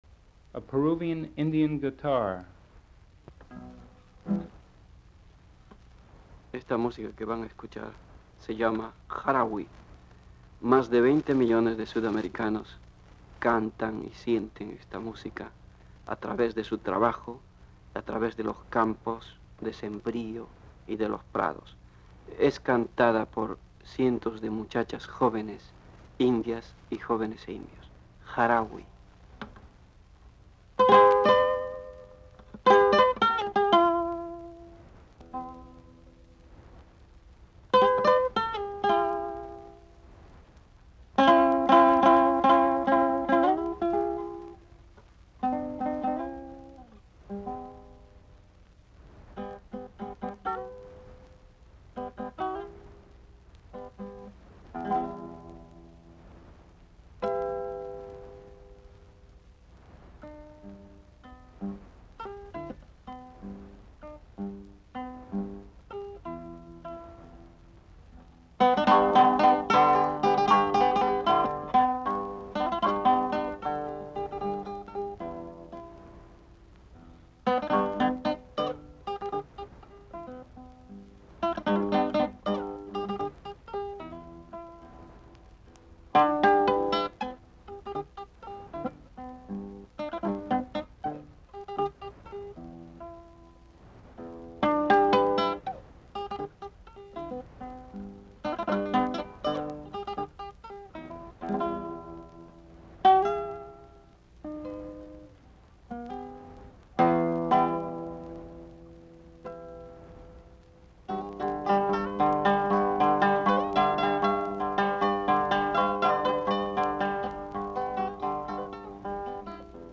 15. A Peruvian Indian guitar